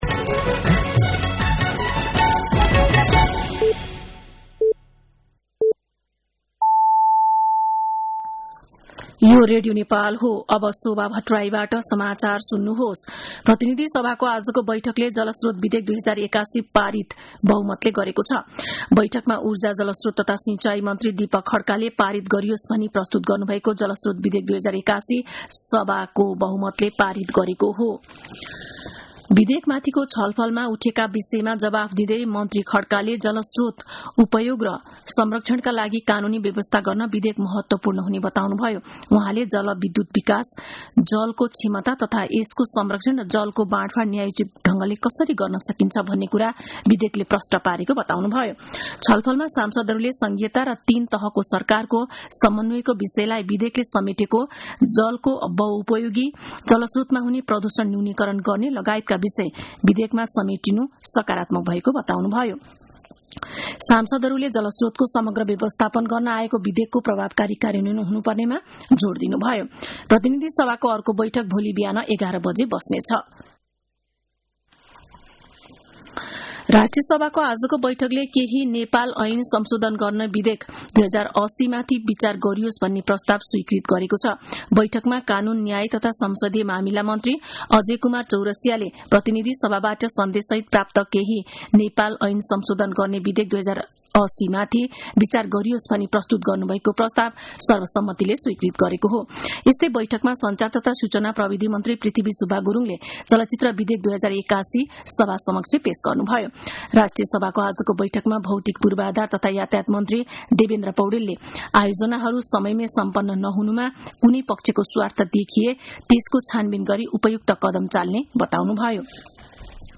साँझ ५ बजेको नेपाली समाचार : ५ जेठ , २०८२
5.-pm-nepali-news-1-1.mp3